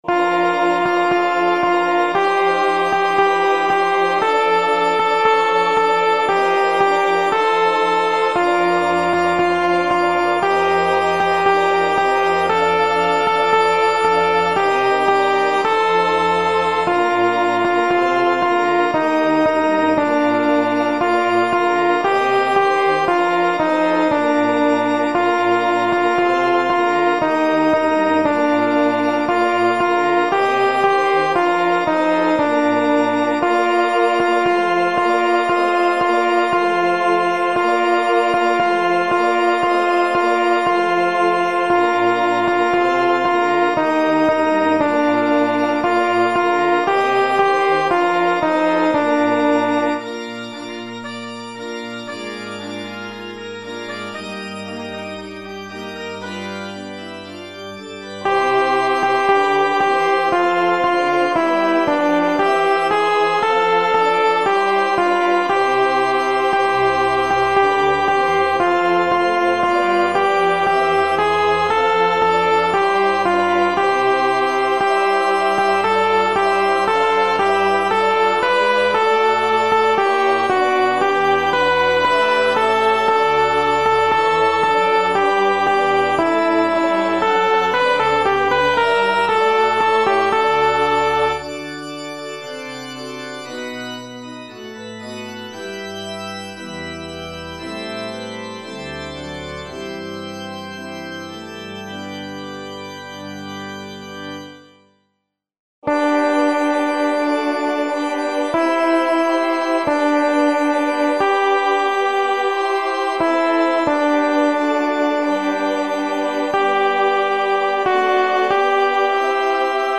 アルト2（フレットレスバス音）
＊テンポはすべて一定にしてあるので音取りのみに使用し、実際に歌うときは楽譜の指示、指揮者を見る。